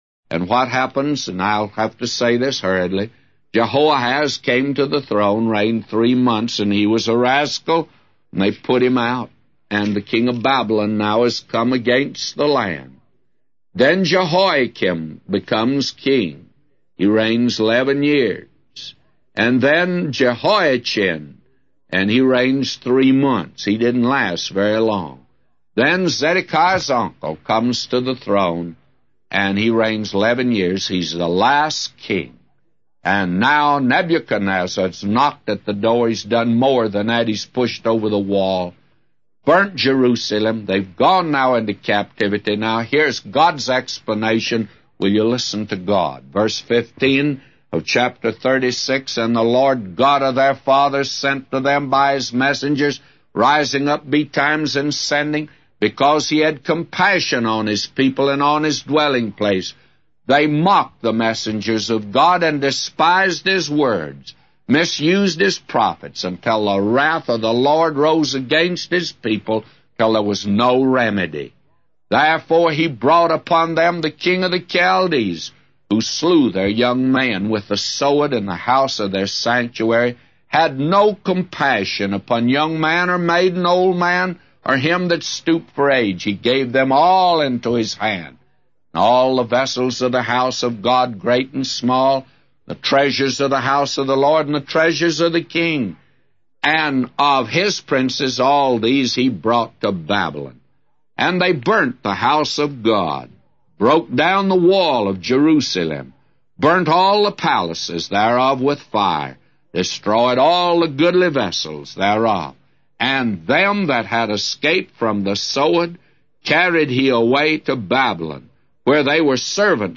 A Commentary By J Vernon MCgee For 2 Chronicles 36:1-999